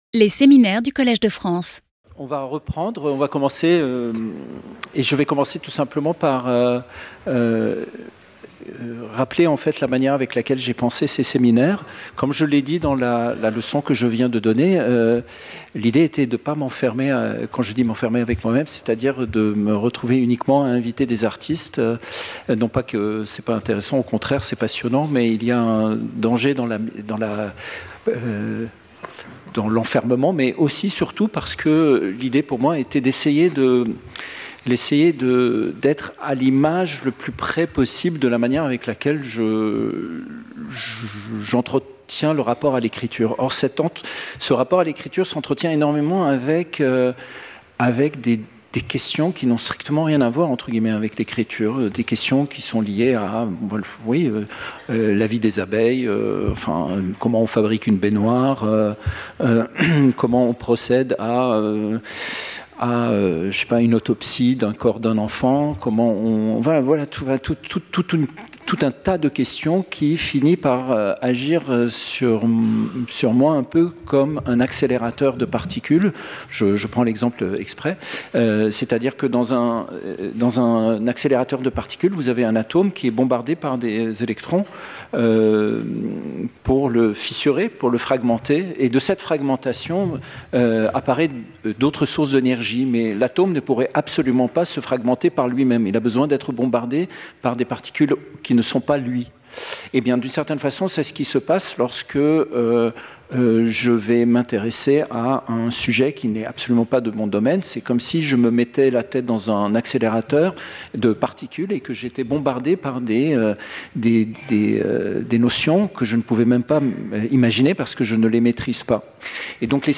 Séminaire